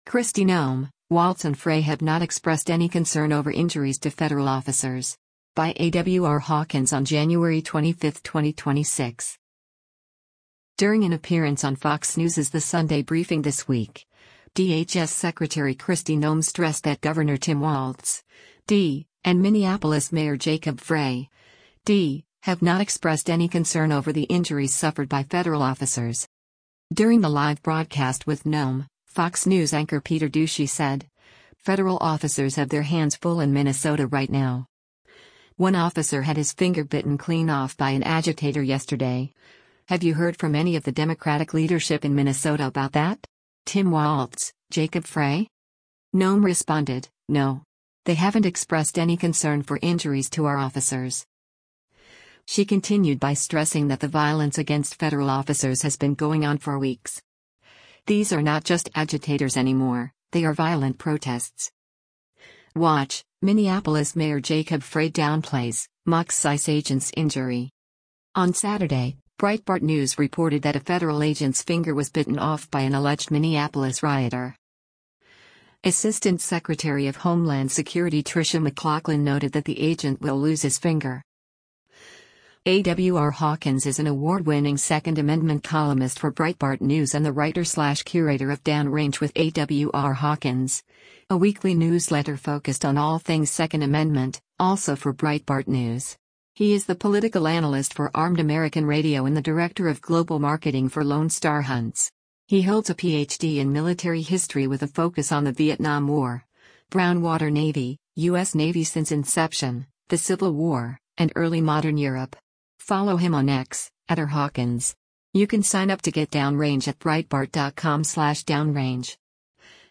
During an appearance on FOX News’s The Sunday Briefing this week, DHS Secretary Kristi Noem stressed that Gov. Tim Walz (D) and Minneapolis Mayor Jacob Frey (D) have not “expressed any concern” over the injuries suffered by federal officers.